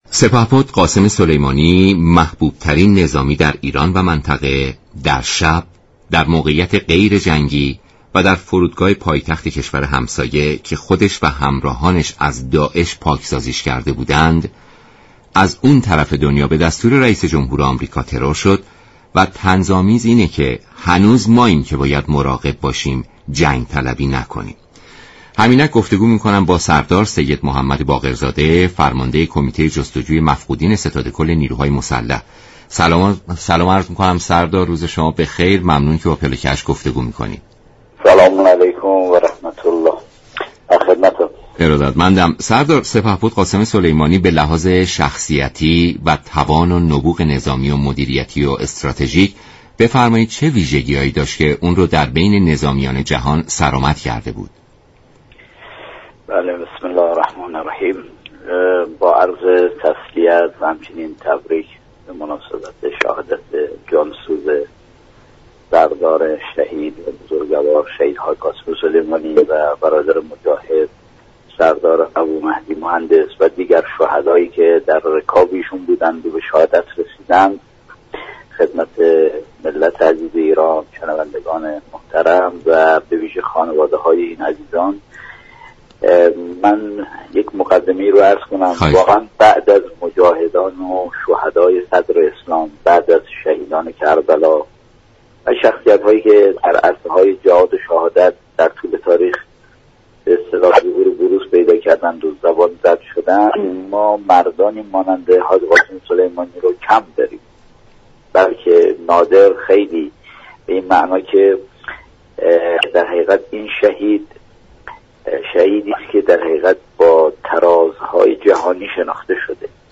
به گزارش شبكه رادیویی ایران، سردار «سید محمد باقرزاده» فرمانده كمیته جستجوی مفقودین ستاد كل نیروهای مسلح در برنامه «پلاك هشت» درباره شخصیت و نبوغ نظامی سردار سلیمانی گفت: دایره نفوذ و عملكرد شهید حاج قاسم سلیمانی، این سردار شجاع را به یك شخصیت جهانی تبدیل كرده است.